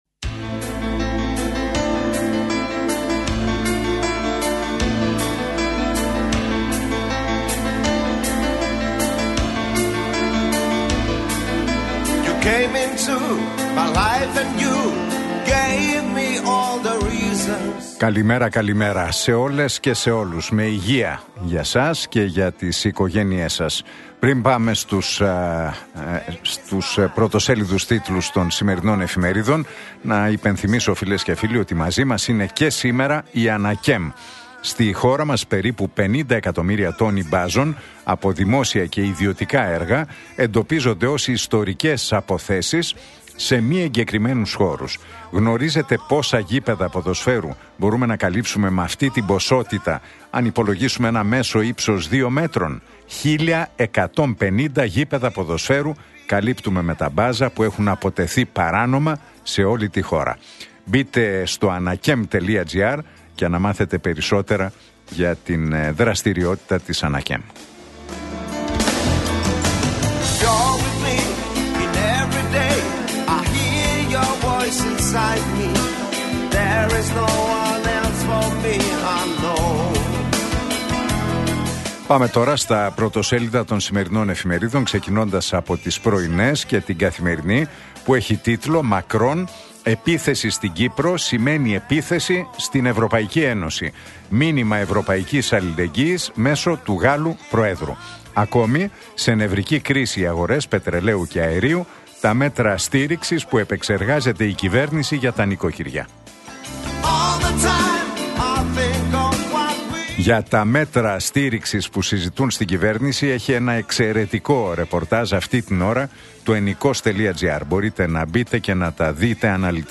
Ακούστε την εκπομπή του Νίκου Χατζηνικολάου στον ραδιοφωνικό σταθμό RealFm 97,8, την Τρίτη 10 Μαρτίου 2026.